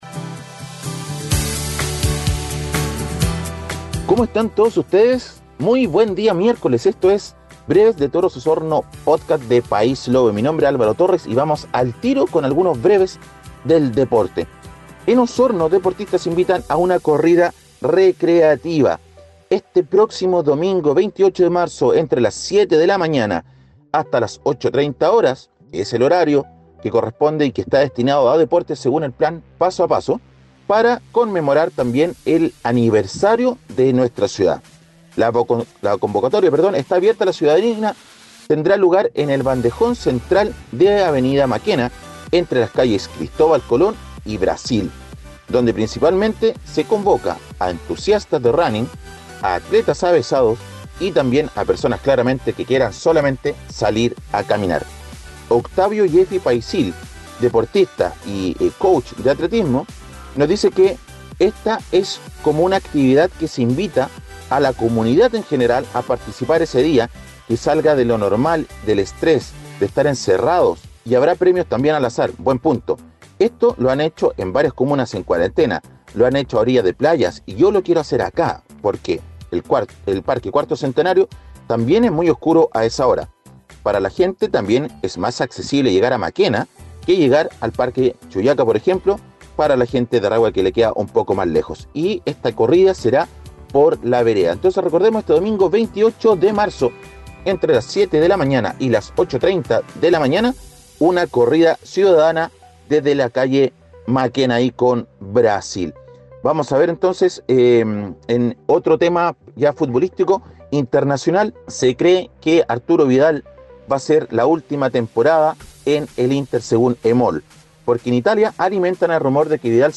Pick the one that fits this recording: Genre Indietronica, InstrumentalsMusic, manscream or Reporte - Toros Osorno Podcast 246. Reporte - Toros Osorno Podcast 246